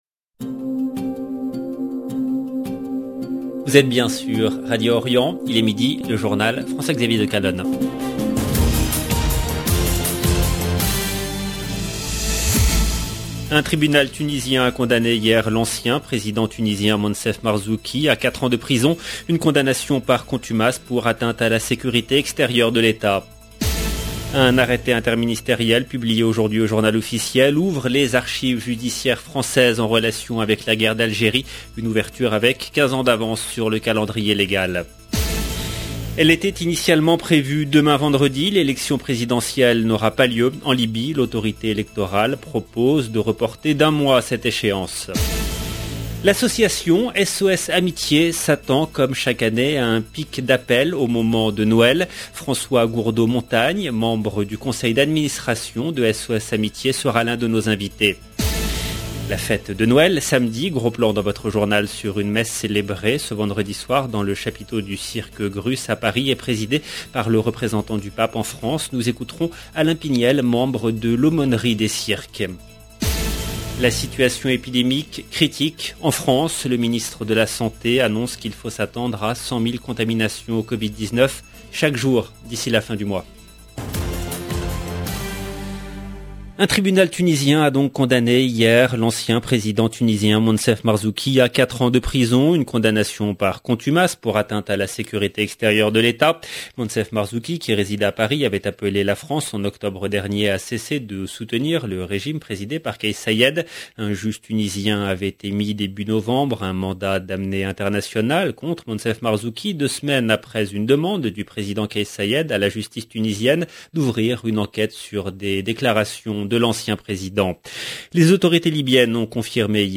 EDITION DU JOURNAL DE 12H EN LANGUE FRANCAISE DU 23/12/2021